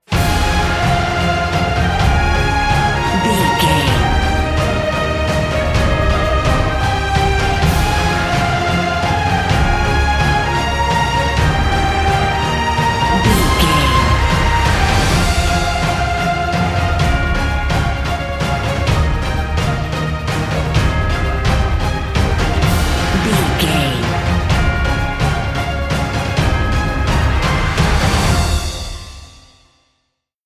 Epic / Action
Fast paced
Aeolian/Minor
Fast
heavy
brass
drums
strings
synthesizers